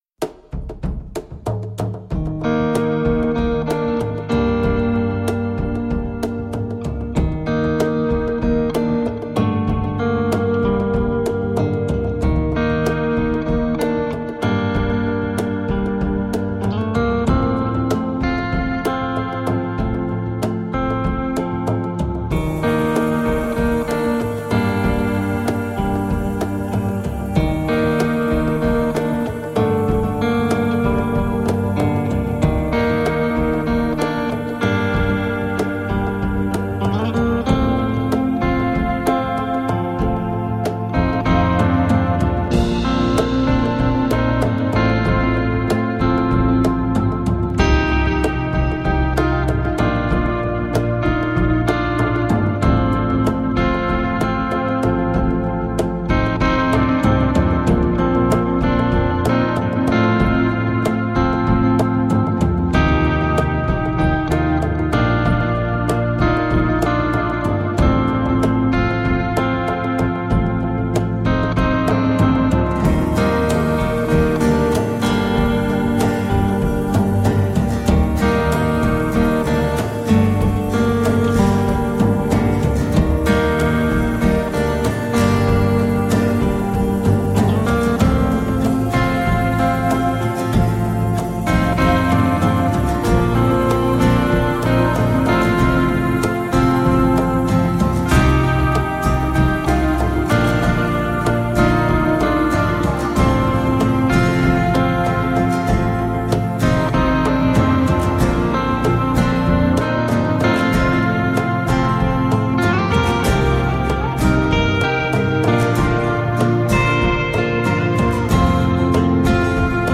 Evocative, melodic and haunting instrumental guitar music.